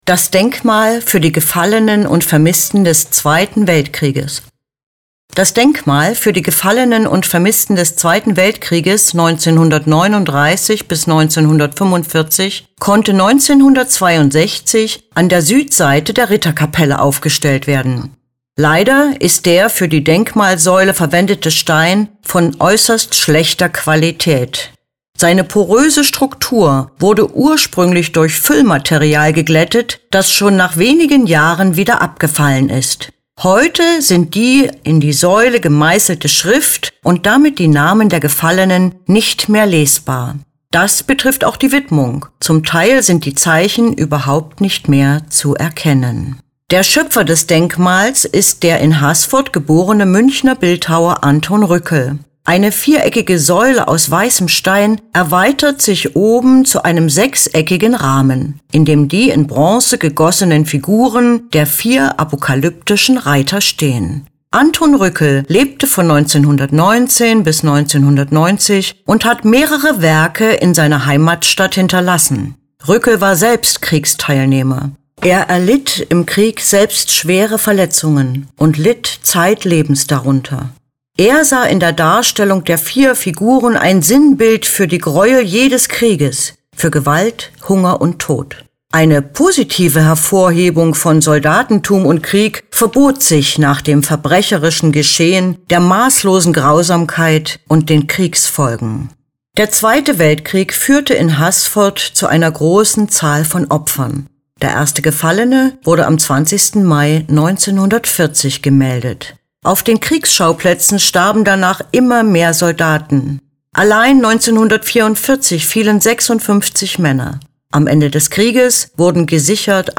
Lassen Sie sich die Infos zu diesem Werk einfach vorlesen.